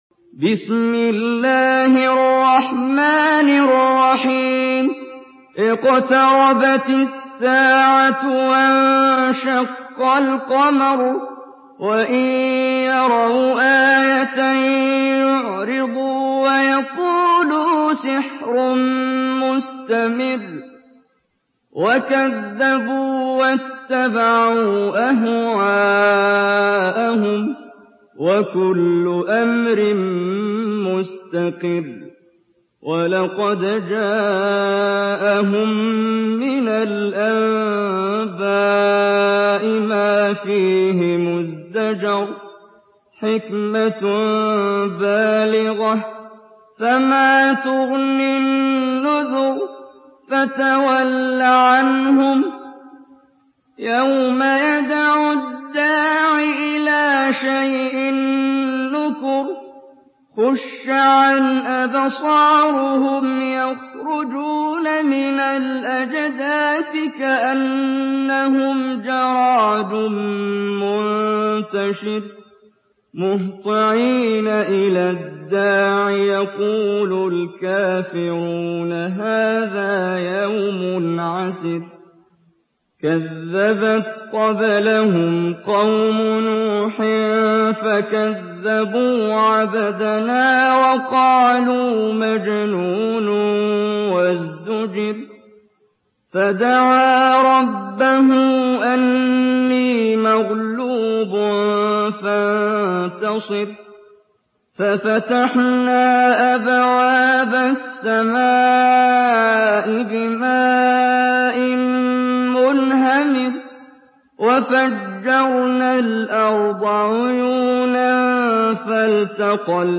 Surah Kamer MP3 by Muhammad Jibreel in Hafs An Asim narration.
Murattal Hafs An Asim